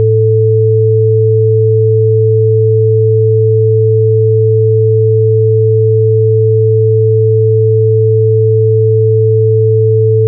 different sounds on different channels, you will find definitely different left and right signals!
stereo-test.wav